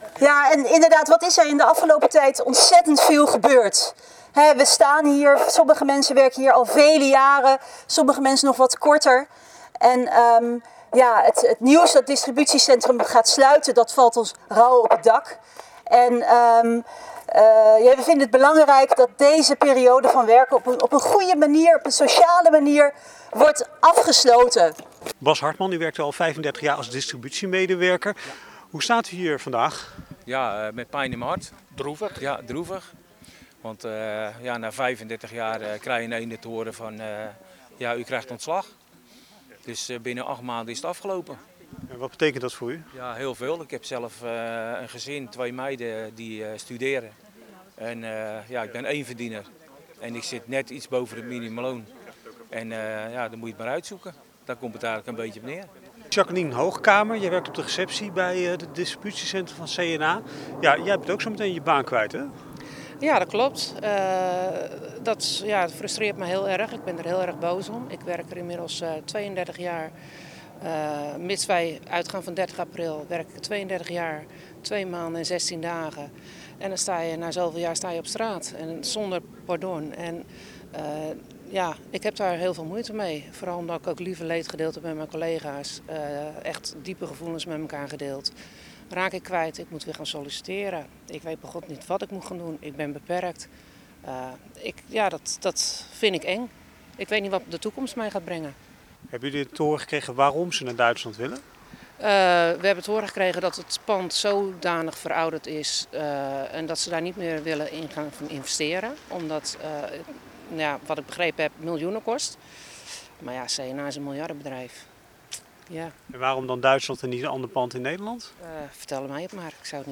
Radioreportage